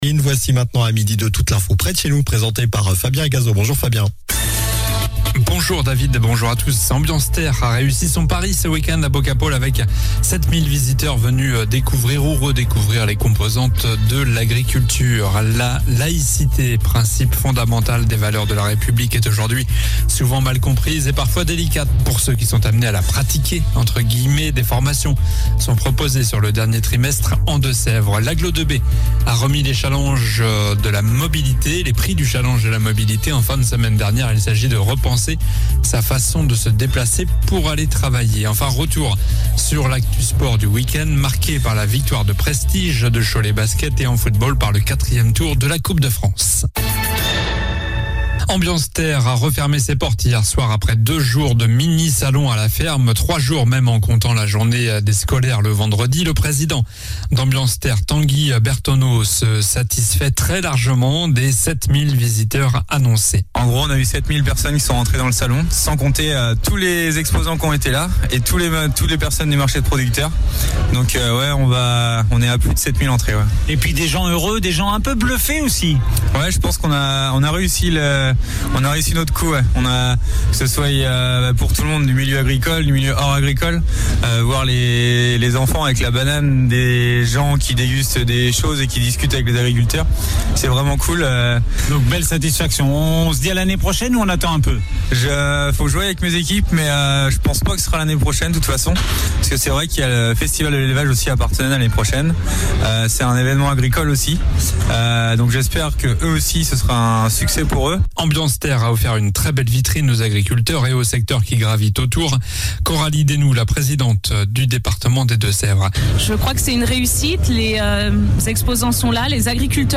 Journal du lundi 26 septembre (midi)